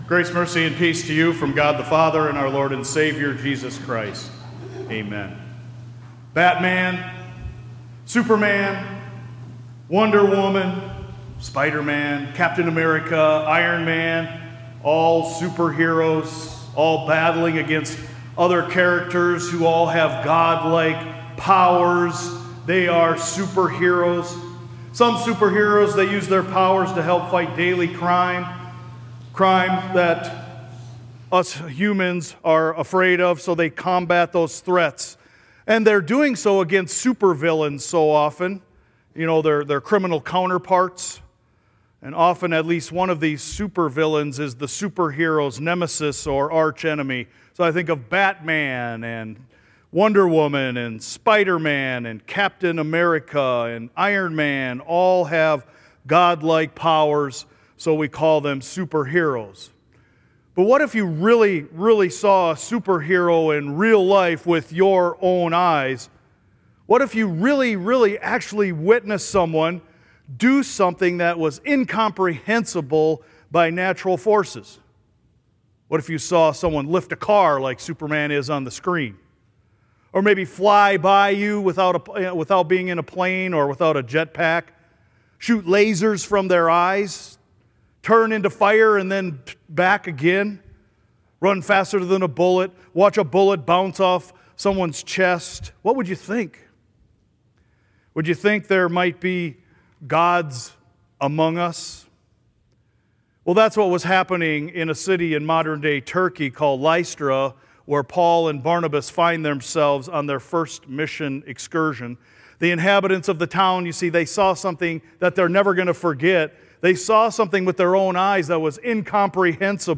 SERMON_223.mp3